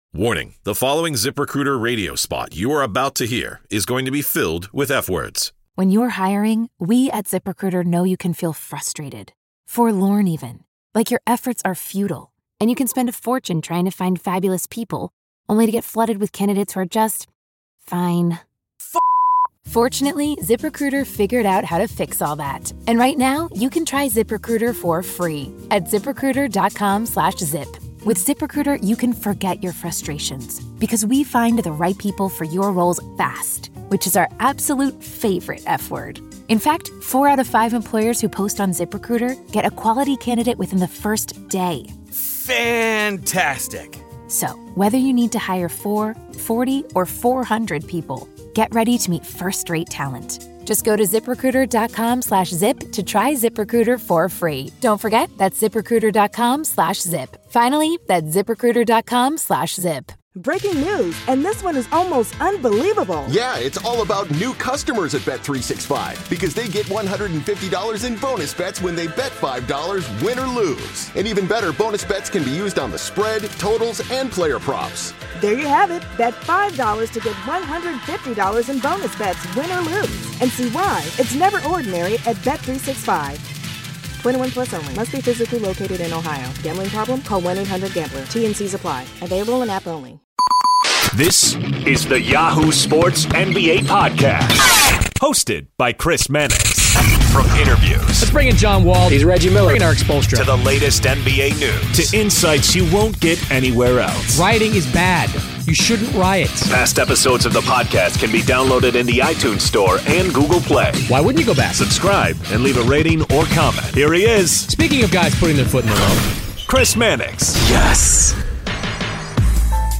Brad Stevens The Crossover NBA Show SI NBA Basketball, Sports 4.6 • 641 Ratings 🗓 7 August 2018 ⏱ 35 minutes 🔗 Recording | iTunes | RSS 🧾 Download transcript Summary Joining Chris Mannix of Yahoo Sports this week is Celtics coach Brad Stevens.